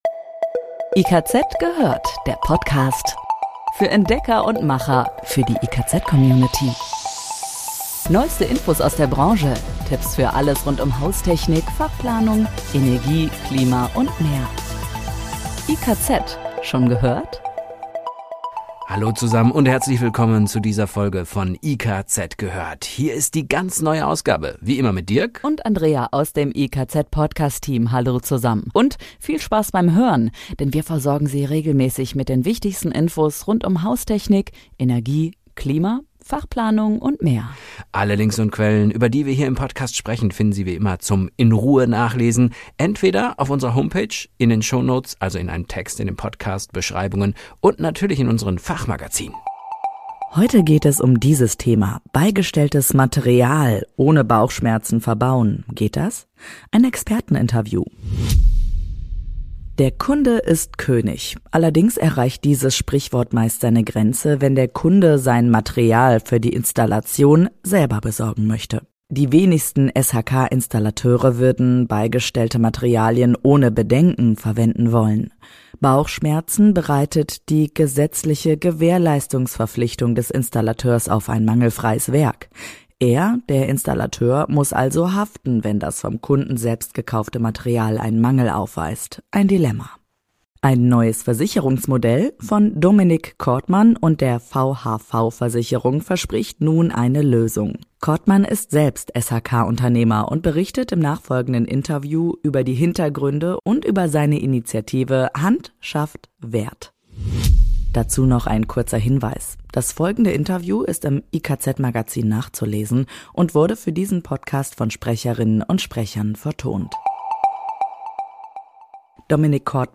*Das Interview ist im IKZ-Magazin nachzulesen und wurde für diesen Podcast von Sprecherinnen und Sprechern vertont* Bauchschmerzen bereitet die gesetzliche Gewährleistungsverpflichtung des Installateurs auf ein mangelfreies Werk.